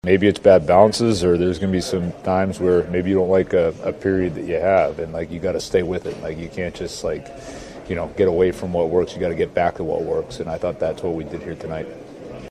Coach Dan Muse says sometimes wins aren’t pretty, but they’re still wins.